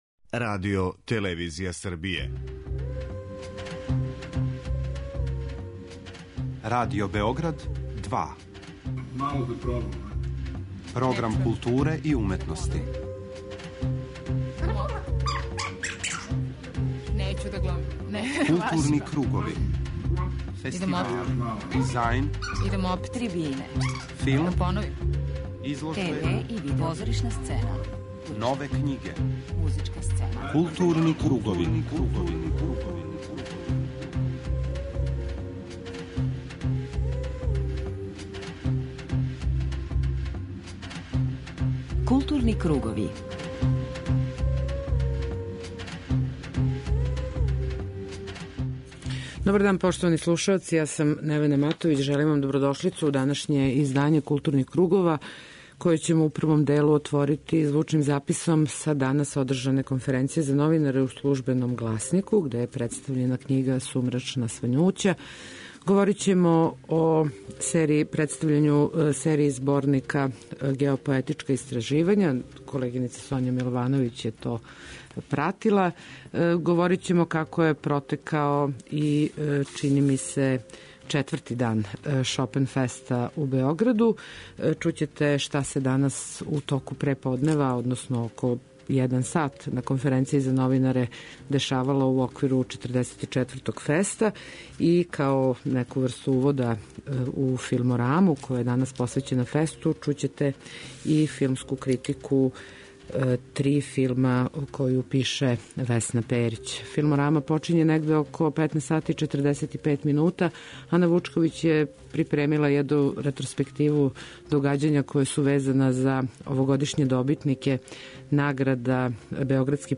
У данашњој емисији чућете Викторију Абрил и Џона Мектирнана, како говоре о својим каријерама, филмској магији, али и ФЕСТ-у и Београду.